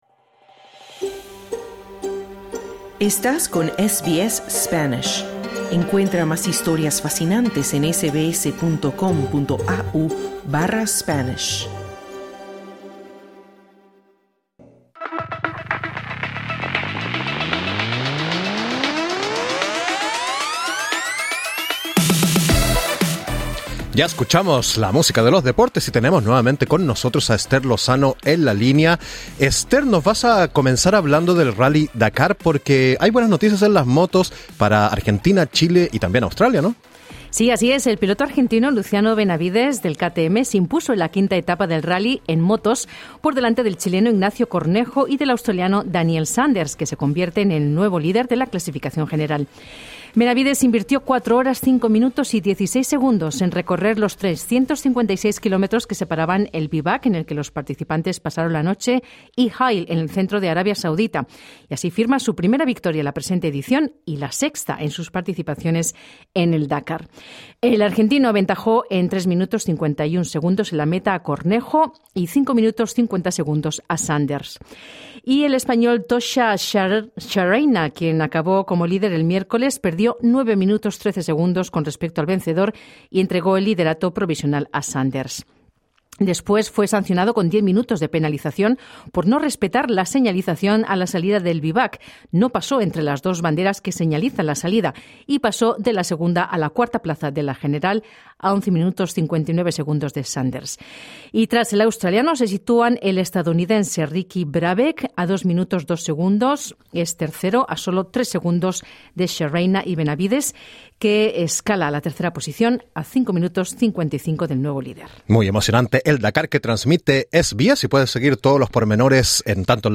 Noticias deportivas 09/1/2026: El piloto argentino Luciano Benavides se impone en la quinta etapa del Rally Dakar en motos, por delante del chileno Ignacio Cornejo y del australiano Daniel Sanders. Australia logra una victoria por 4-1 en la serie Ashes, tras vencer a Inglaterra en el último Test por cinco wickets en el quinto día.